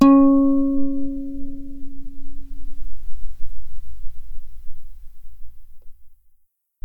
acoustic-guitar
C#4_mf.mp3